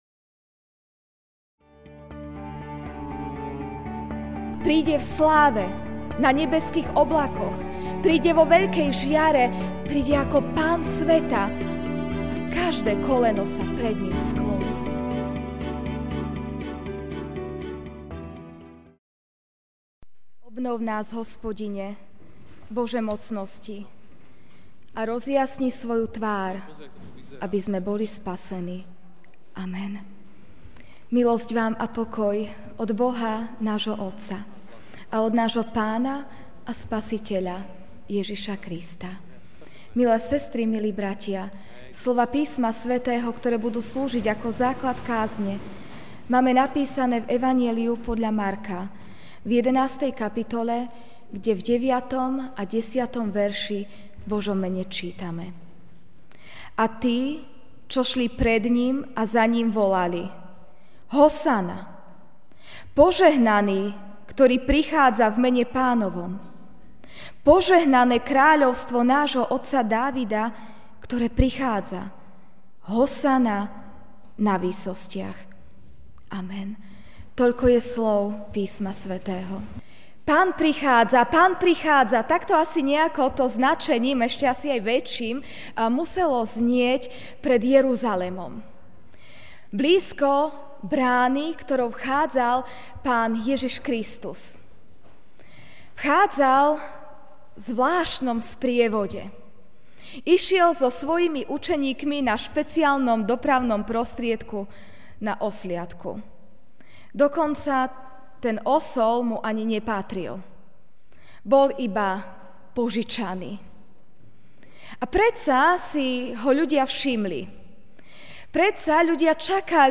Ranná kázeň: Pán prichádza (Mk 11,9-10) 'A tí, čo šli pred Ním a za Ním, volali: Hosana!